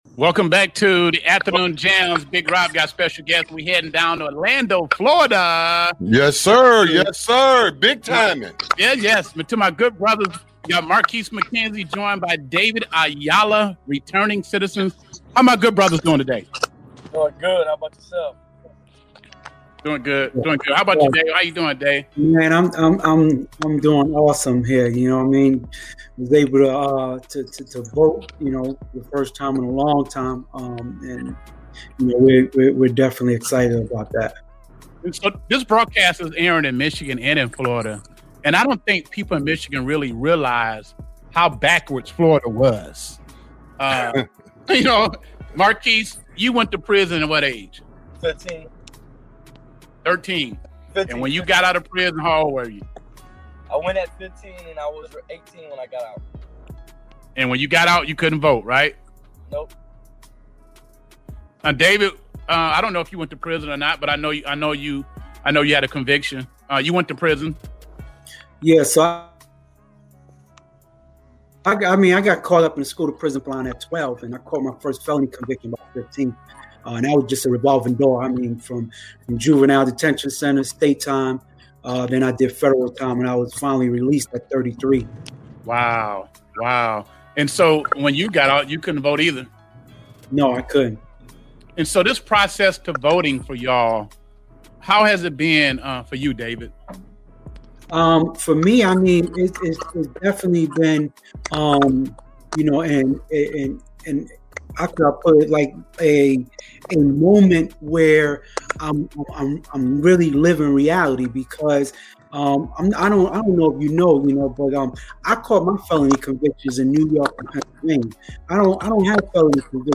Interview with Returning Citizens